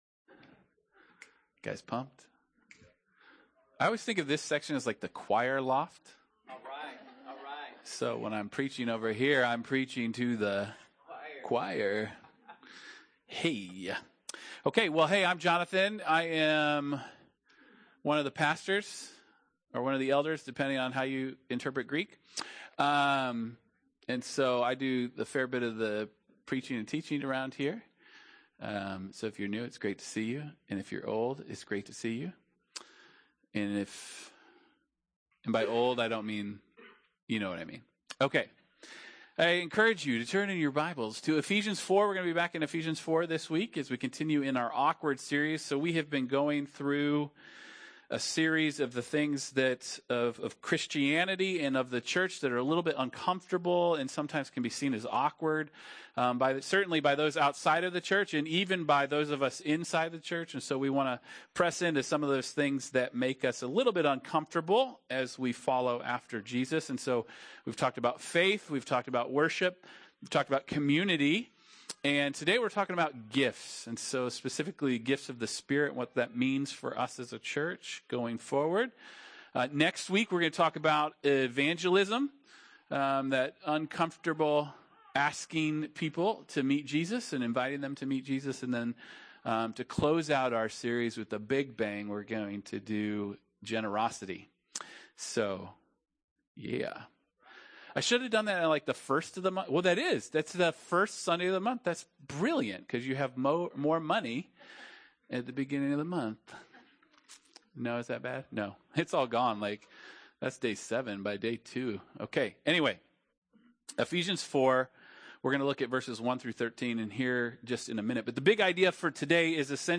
The church is supernaturalized by the Holy Spirit for maturity and mission. Sermon notes Small group questions